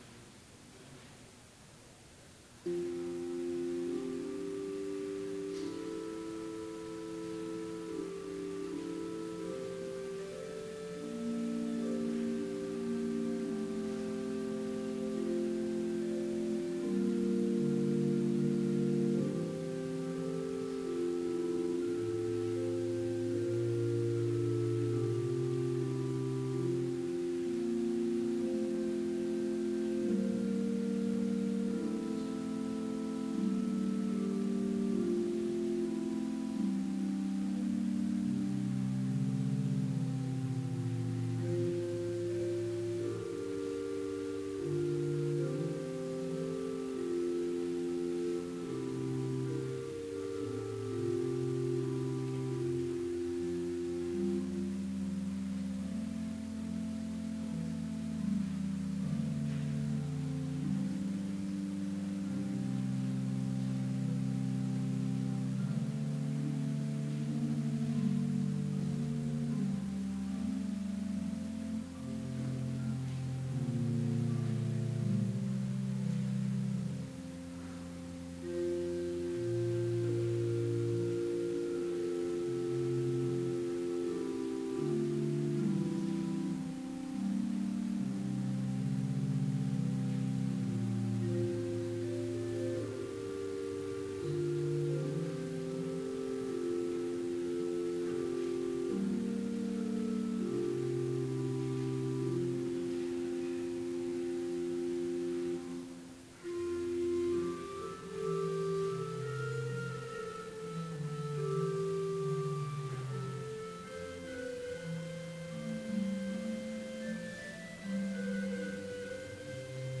Complete service audio for Chapel - December 5, 2016